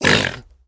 25w18a / assets / minecraft / sounds / mob / piglin / hurt1.ogg
hurt1.ogg